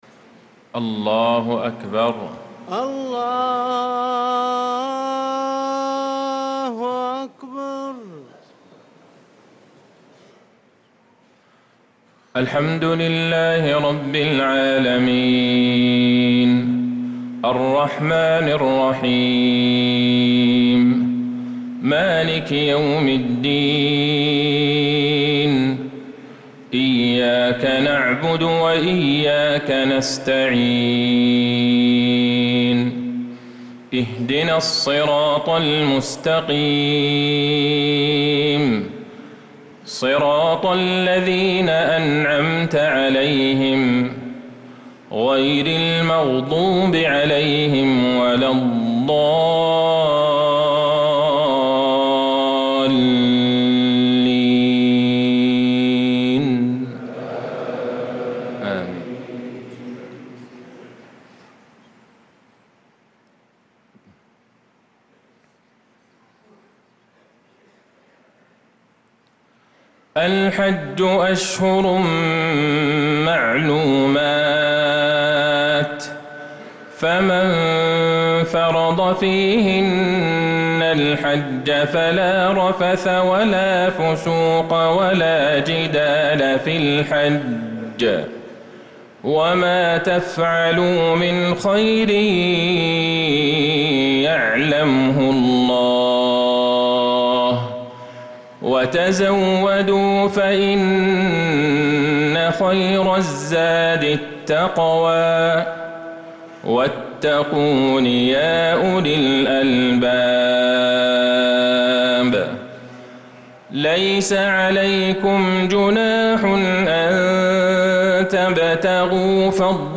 صلاة العشاء للقارئ عبدالله البعيجان 18 ذو الحجة 1445 هـ
تِلَاوَات الْحَرَمَيْن .